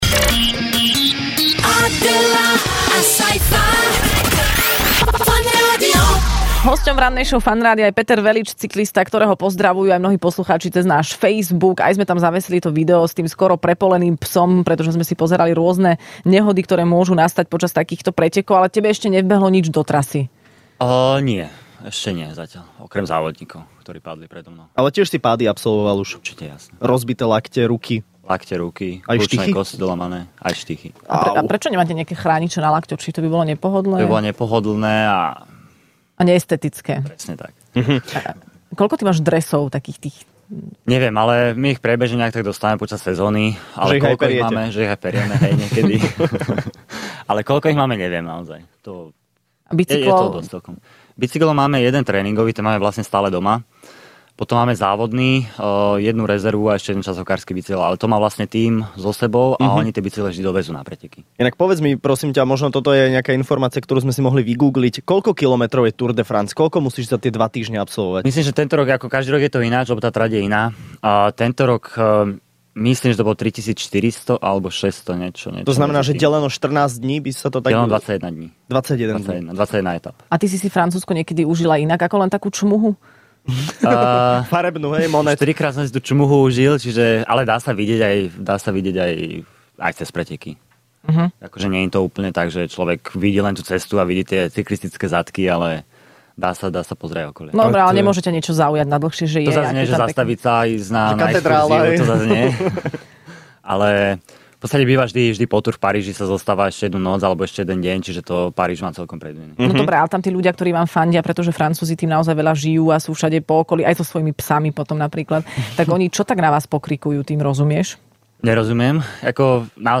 Hosťom v Rannej šou bol cyklista Peter Velits, ktorý sa vrátil z legendárnej Tour de France, kde sa umiestnil na celkovo vynikajúcom 19. mieste.